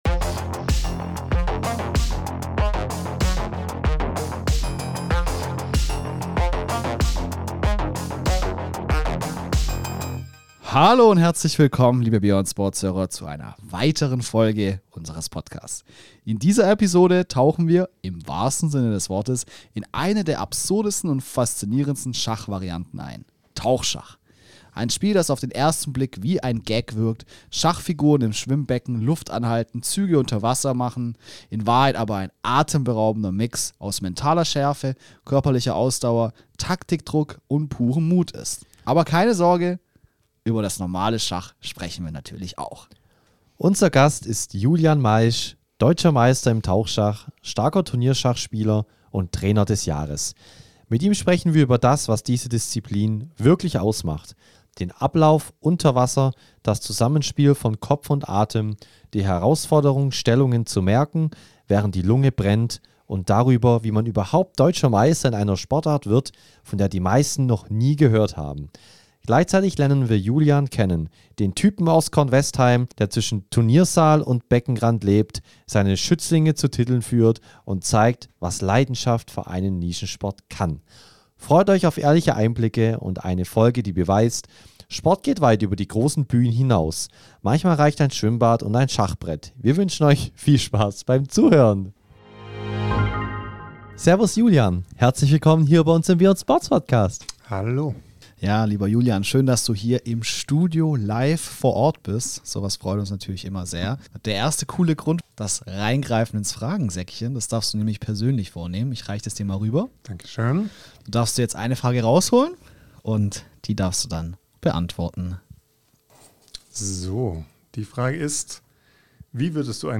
im Gespräch ~ Mixed-Sport Podcast